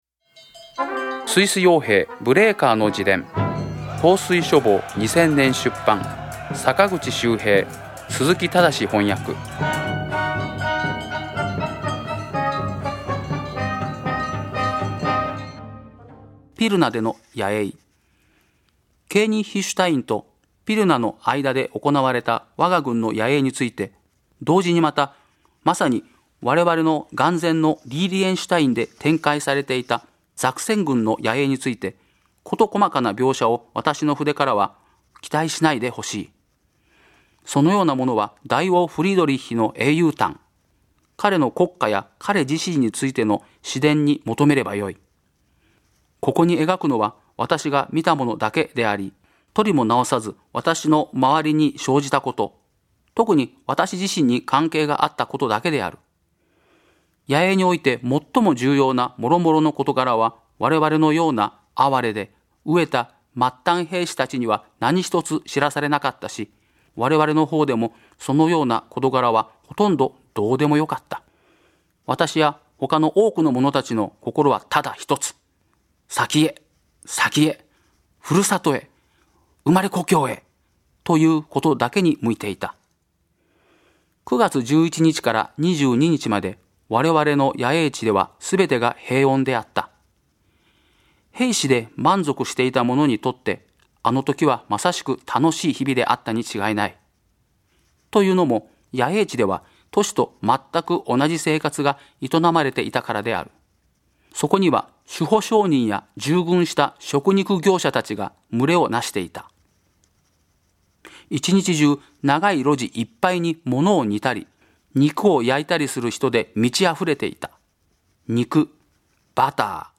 朗読『スイス傭兵ブレーカーの自伝』第57回